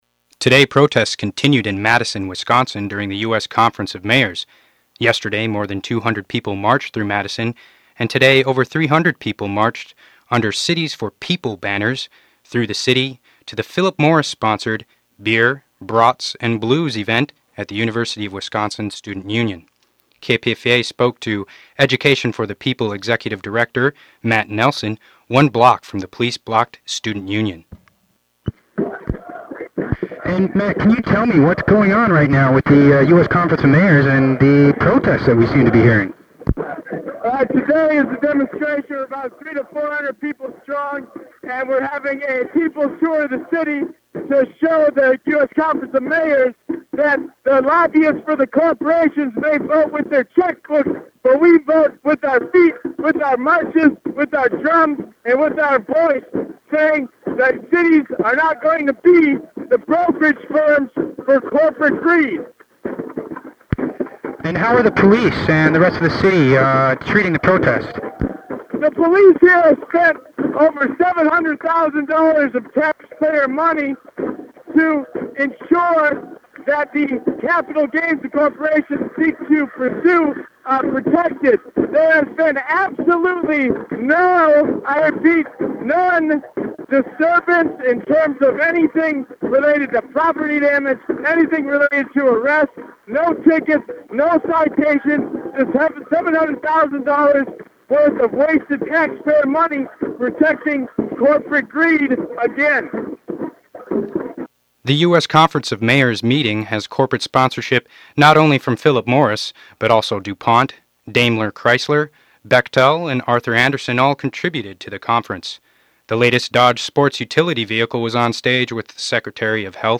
KPFA News 94.1 FM coverage of the U.S. Conference of Mayors in Madison, Wisconsin.